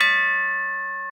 bell_A5.ogg